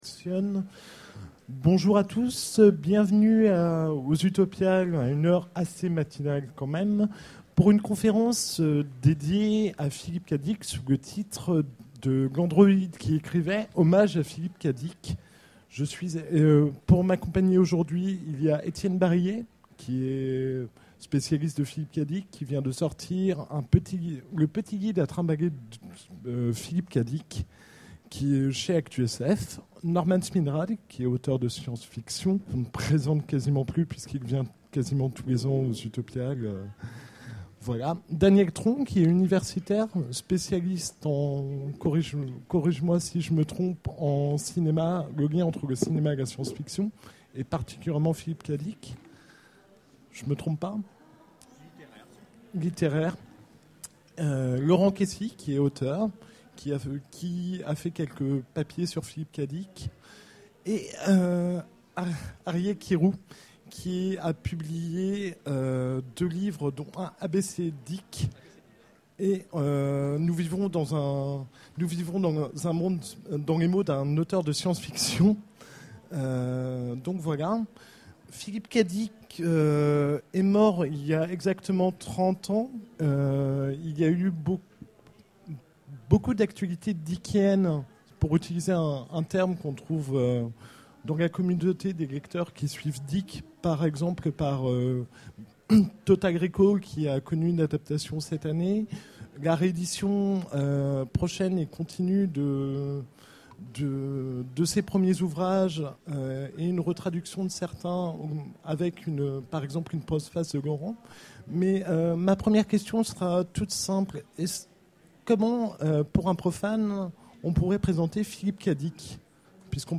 Utopiales 12 : Conférence L’Androïde qui écrivait
Conférence